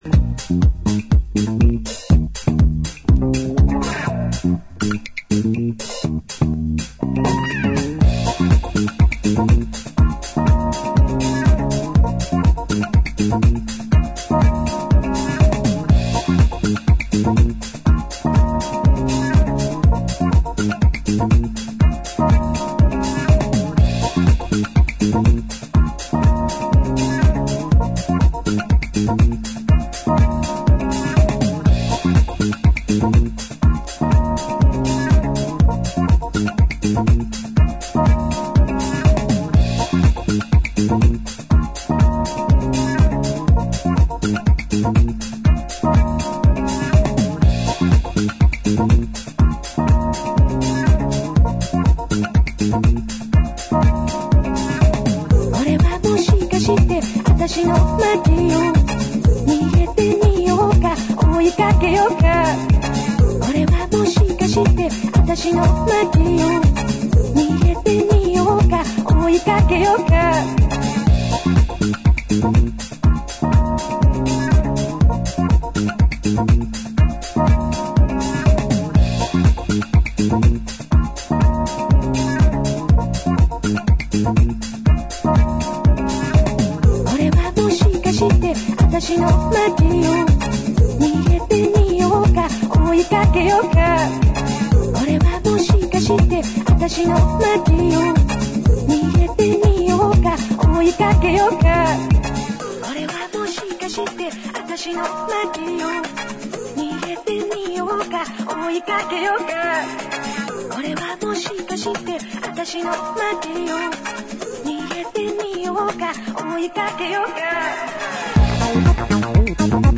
ジャンル(スタイル) HOUSE / DISCO / RE-EDIT